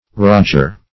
roger - definition of roger - synonyms, pronunciation, spelling from Free Dictionary
Roger \Rog"er\, n. [From a proper name Roger.]